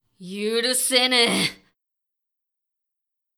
「怒」のタグ一覧
ボイス
リアクション中性中音